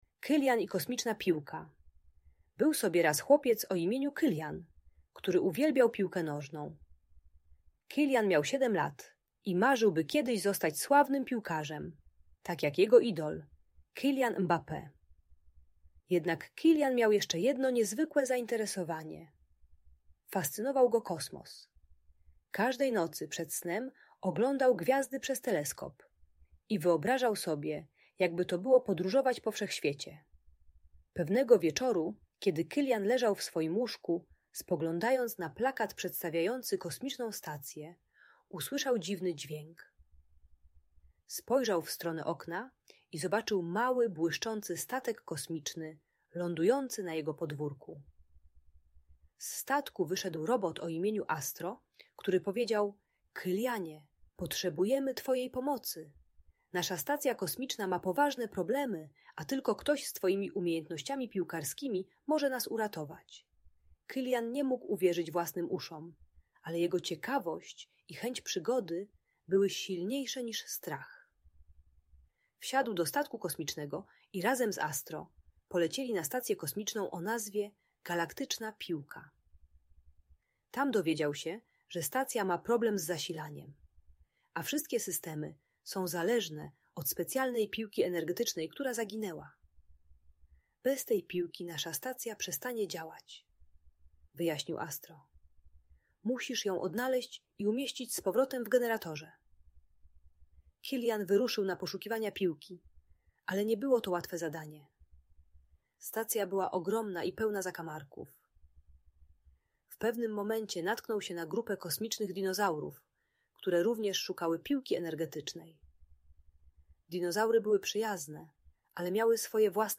Kylian i Kosmiczna Piłka - Audiobajka dla dzieci